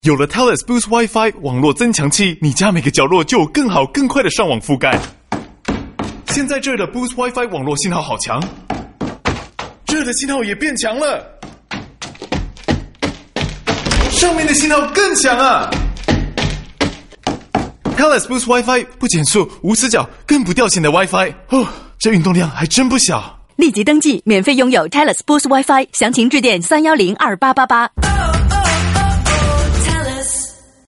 To promote its Boost internet services, binaural radio ads in Cantonese, Mandarin, Hindi and Punjabi moved its narrator around the left and right stereo channels to make it sound like they were moving around the listener’s house, marveling at the signal strength.